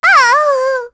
Luna-Vox_Dead_b.wav